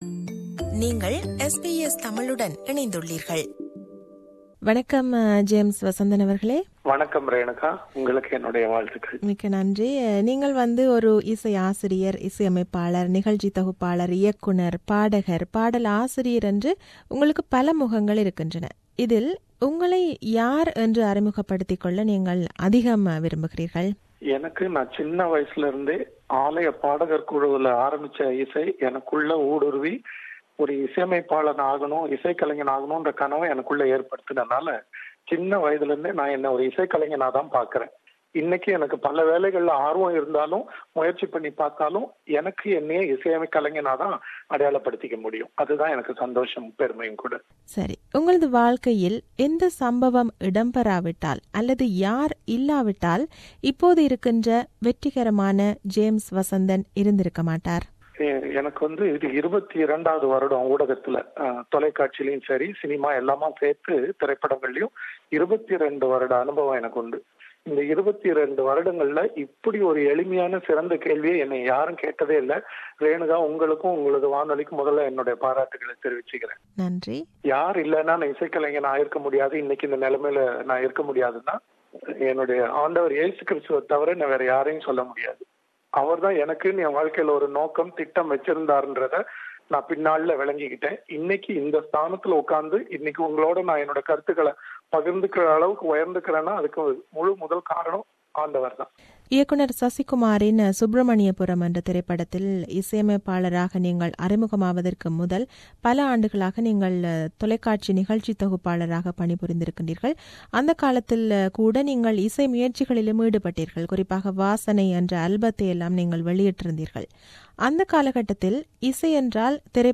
An Interview with James Vasanthan-P01